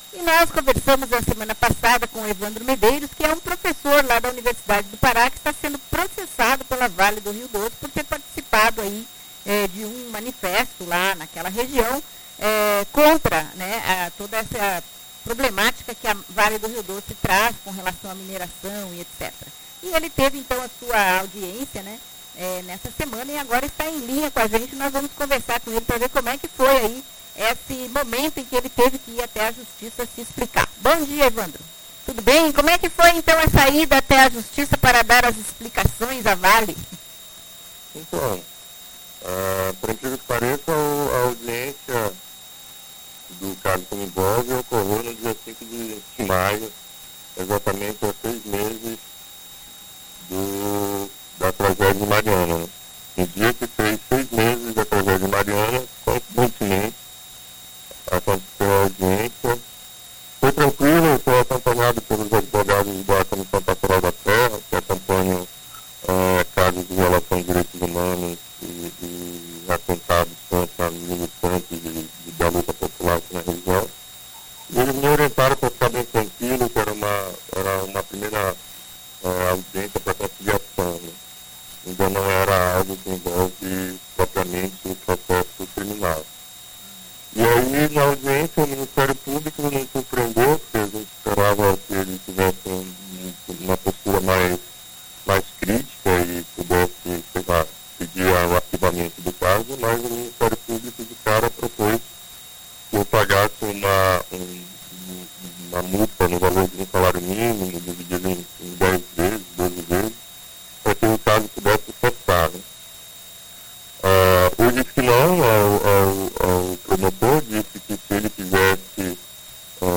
Entrevistas radiofônicas